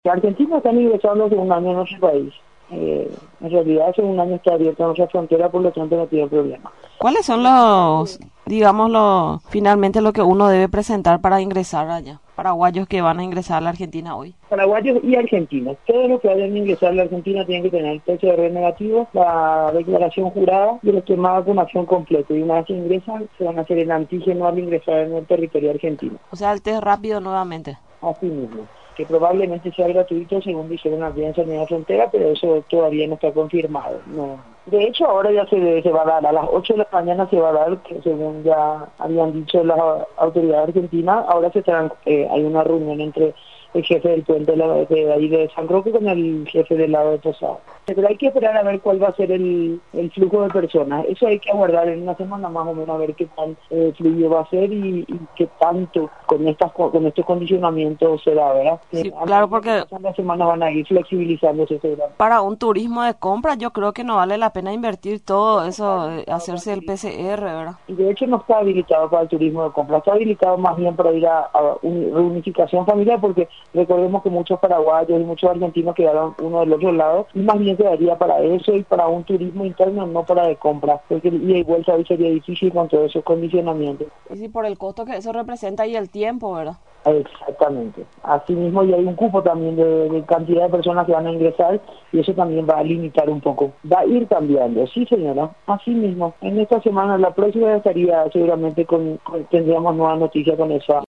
En conversación con Radio Nacional del Paraguay, explicó que para el ingreso a Paraguay, se requerirá el PCR negativo o el esquema completo de vacunación contra el coronavirus.